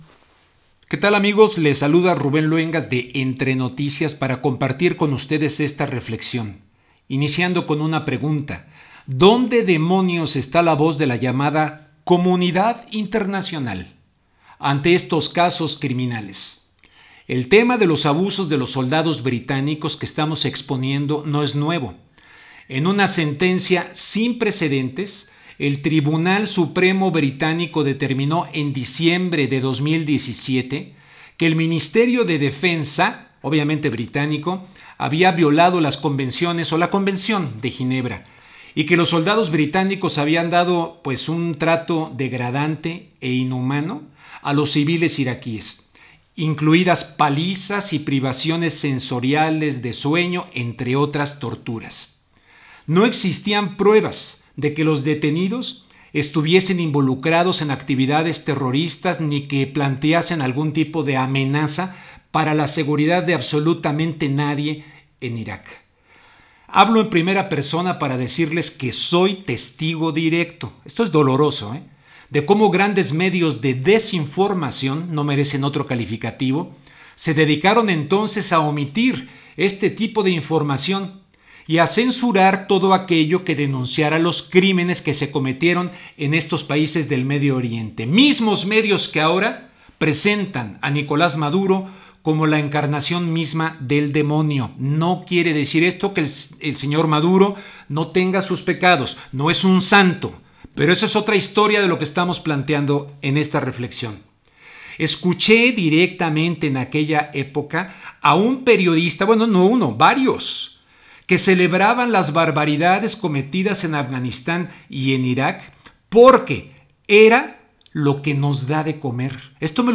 Reflexión en audio